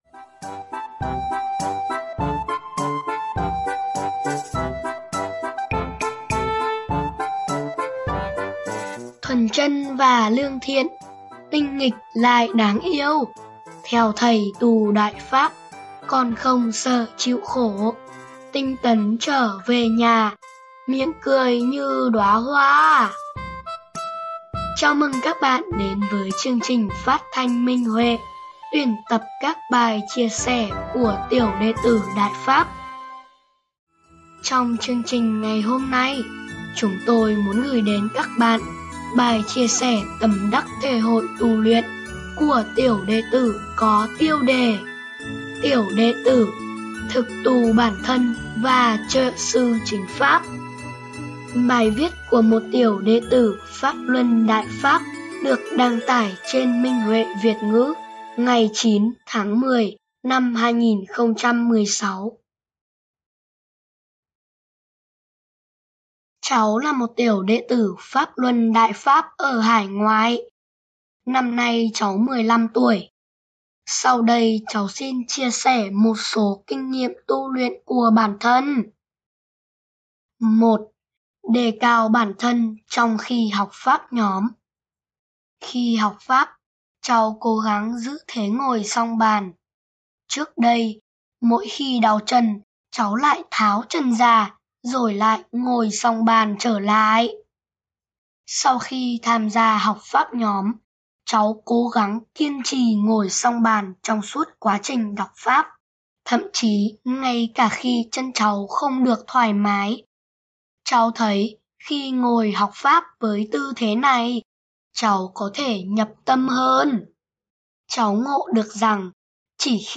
Chương trình phát thanh Số 31: Bài chia sẻ của tiểu đệ tử tại Trung Quốc có tiêu đề Tiểu đệ tử: Thực tu bản thân và trợ Sư chính Pháp.